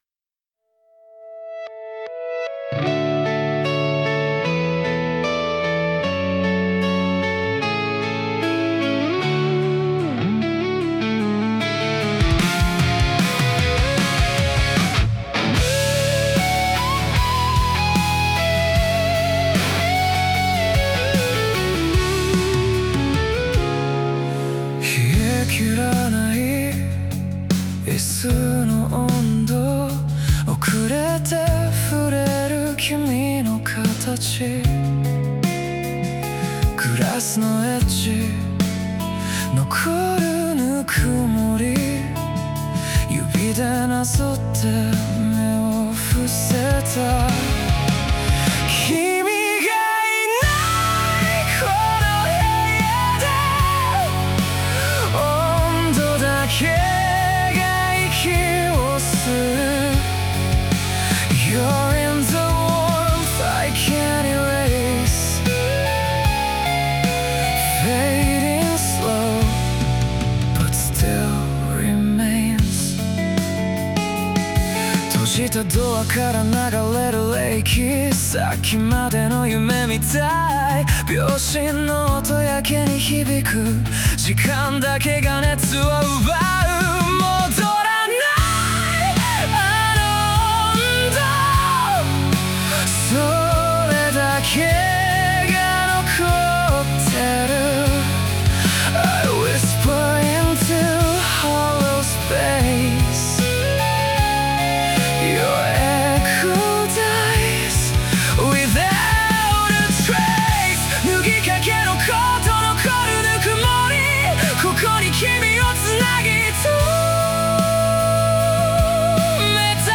男性ボーカル
イメージ：インディーロック,邦ロック,J-ROCK,男性ボーカル,メランコリック